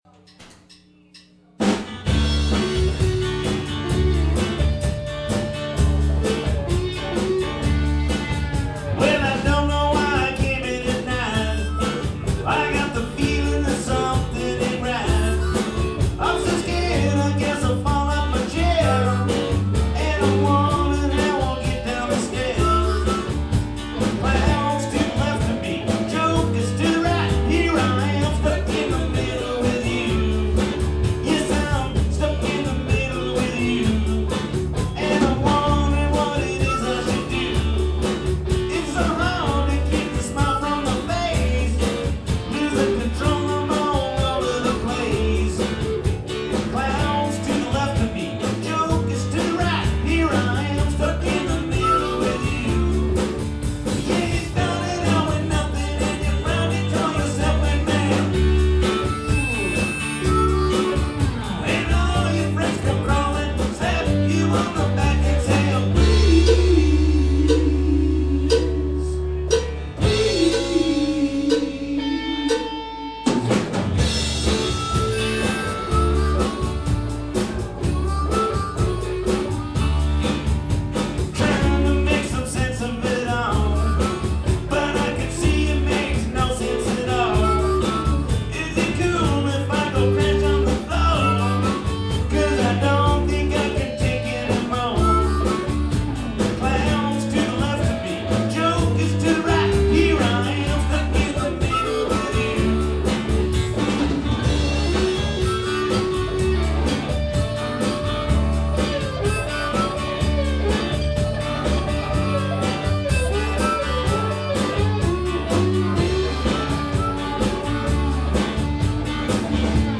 Guitar, Keyboards, Vocals
Bass, Vocals
Guitar, Vocals, Keys, Harmonica
Drums, Vocals